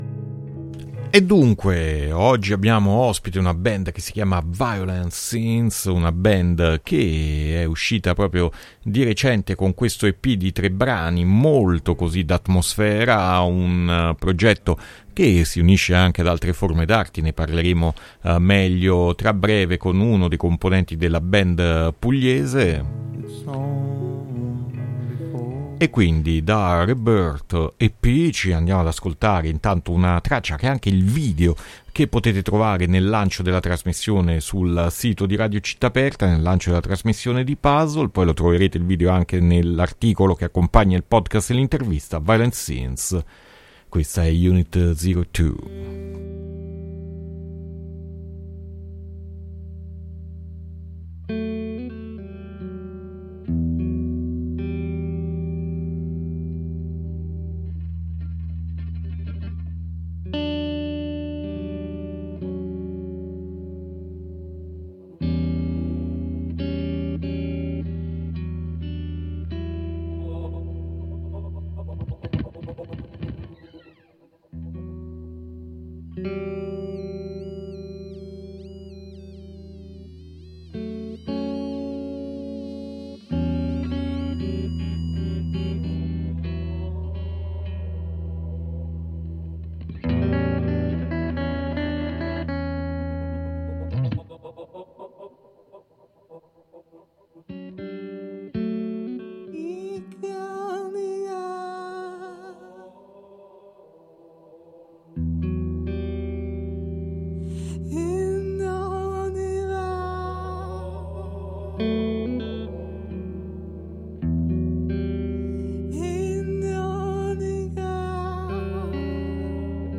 Intervista Violent Scenes a Puzzle 7-2-2022